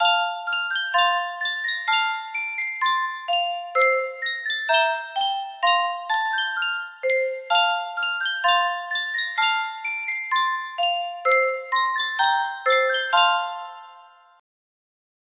Sankyo Music Box - Long Long Ago DiM music box melody
Full range 60